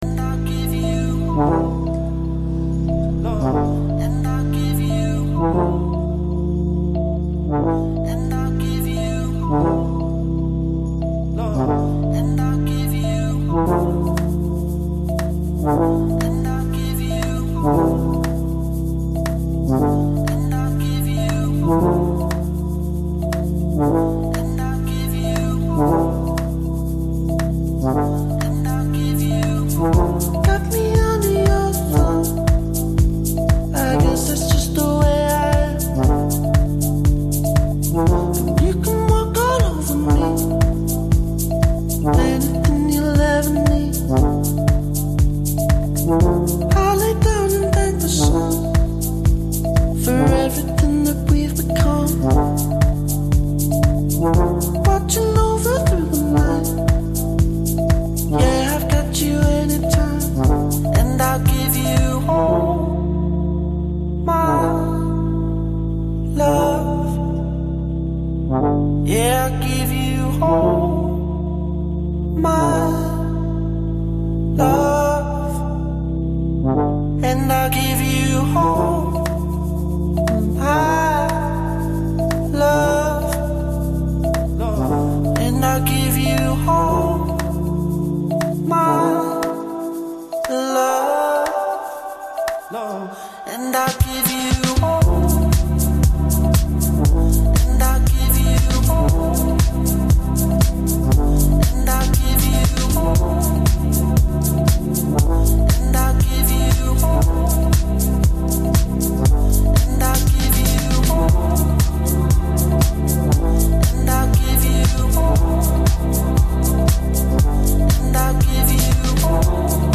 lots of electro y'all, not a lot of talk